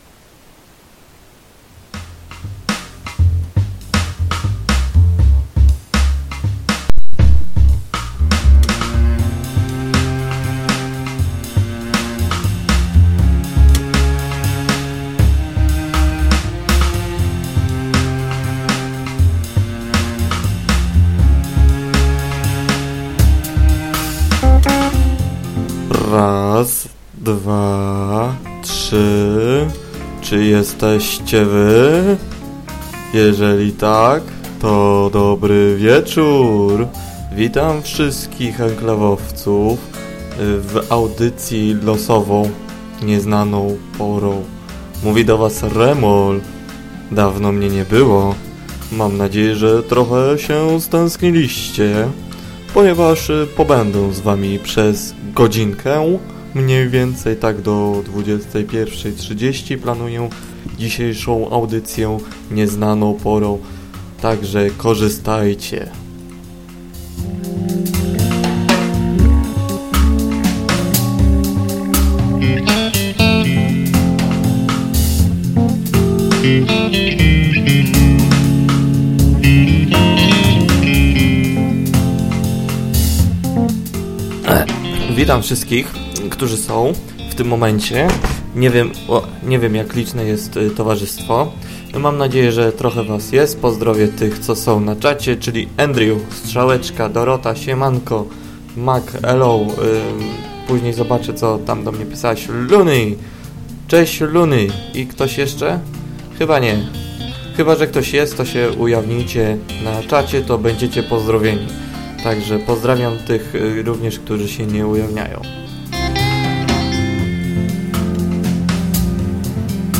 Dzwoniących mało, za to sugestii z czata nieco więcej.